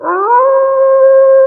Vox [ Wolve ].wav